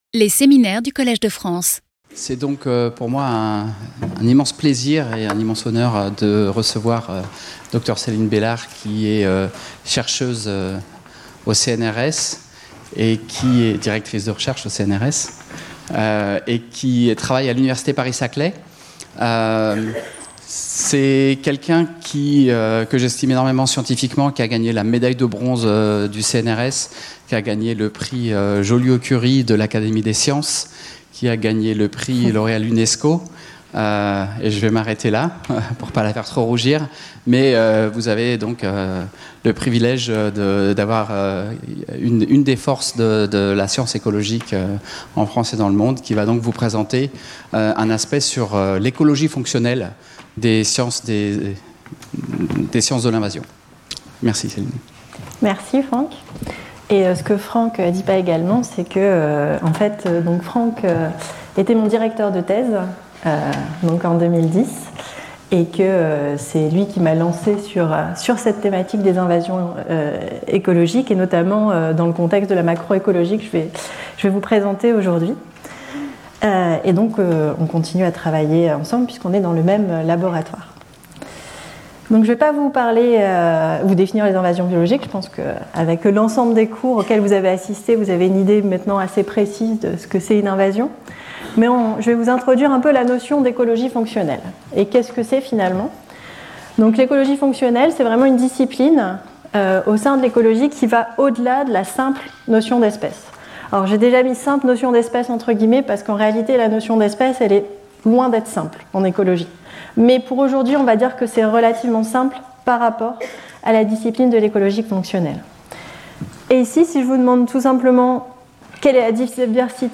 Ce séminaire abordera les conséquences des invasions biologiques sur ces différentes dimensions à travers des exemples concrets de perturbations écologiques pour différents groupes tels que les oiseaux, les mammifères, les poissons ou encore les reptiles et les amphibiens.